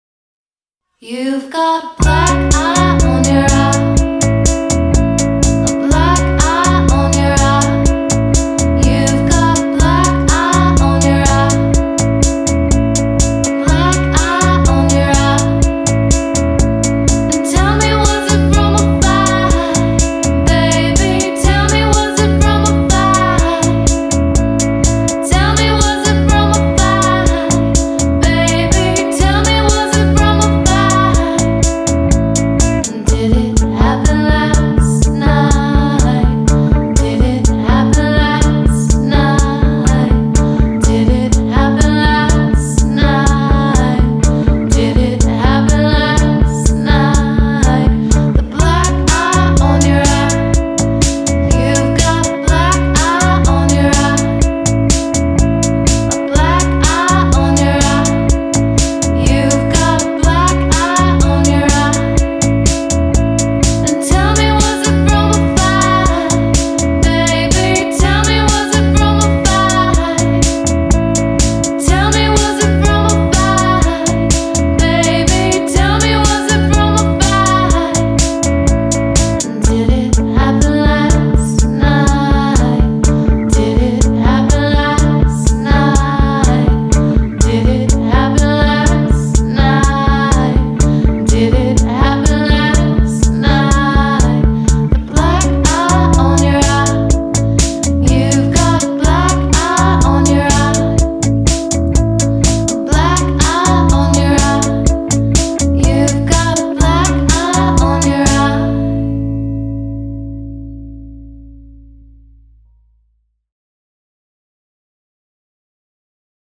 Indie Indiepop Dreampop